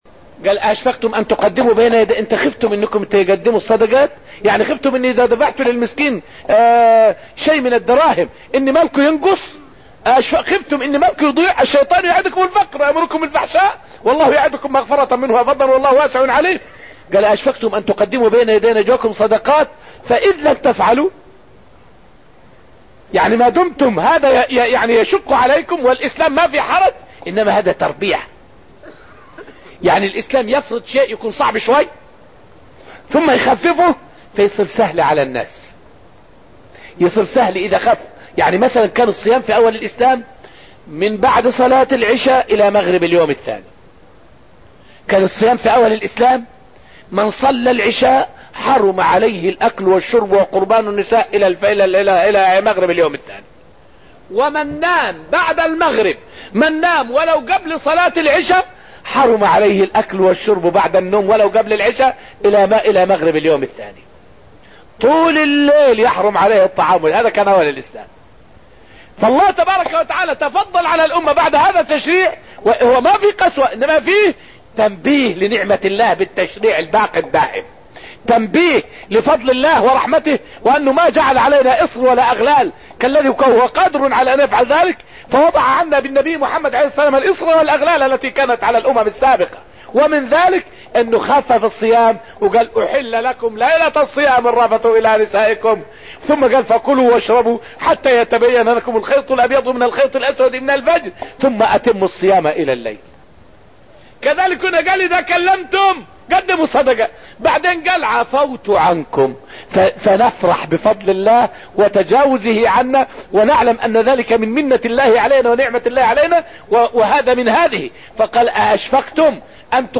فائدة من الدرس التاسع من دروس تفسير سورة المجادلة والتي ألقيت في المسجد النبوي الشريف حول نسخ آية {فقدموا بين يدي نجواكم صدقة} دليل على رحمة الله بعباده المؤمنين.